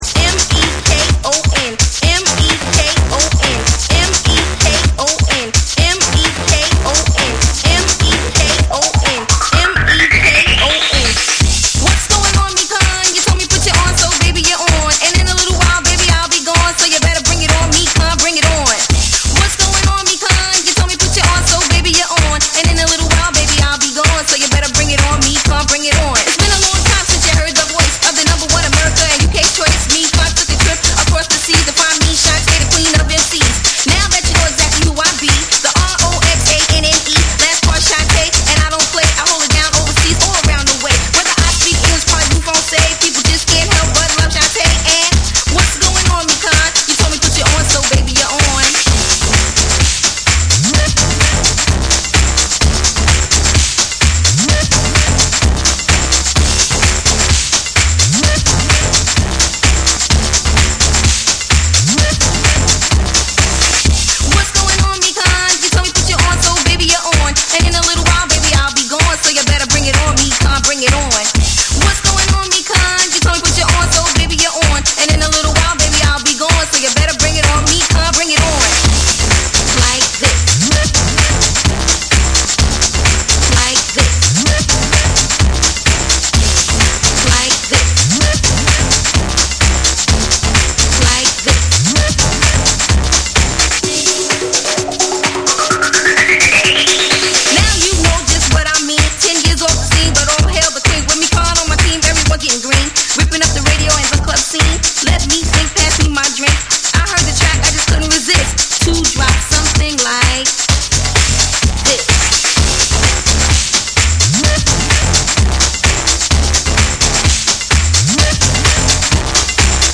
フィメールMC
BREAKBEATS